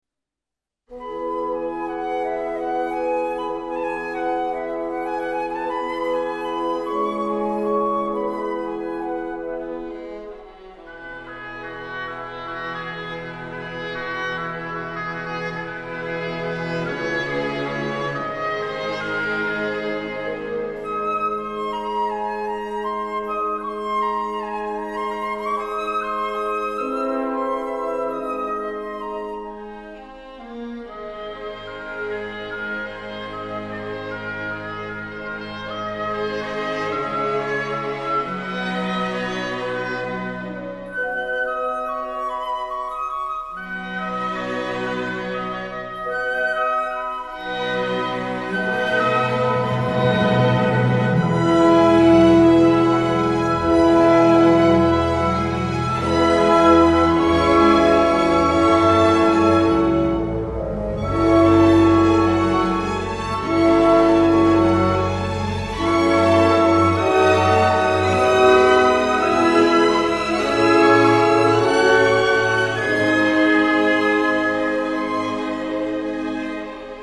First we heard Grieg’s suite no.1 op.46 da Peer Gynt. It’s a piece everyone knows and loves, particularly the descriptive “morning” shimmering like sunrays on water!